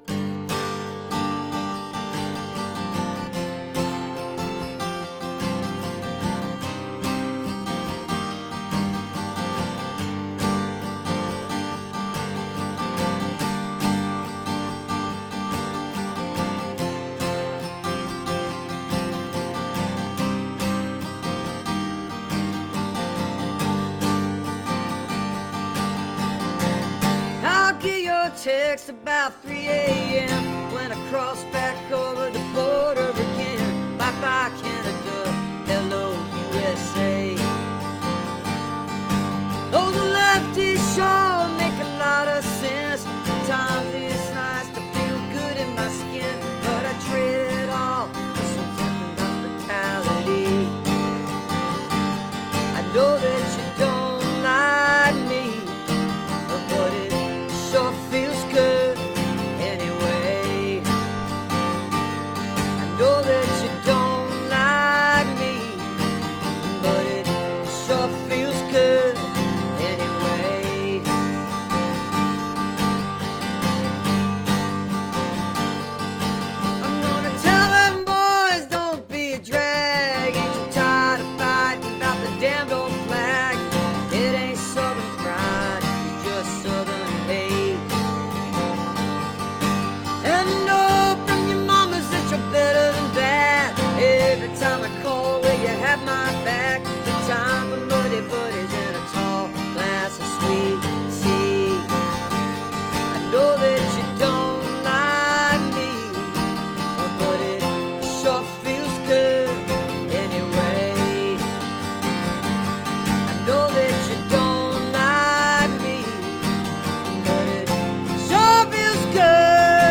(captured from the facebook live stream)